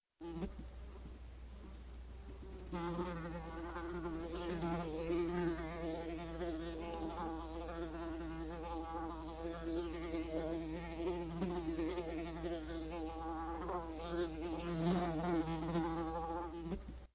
flies-sound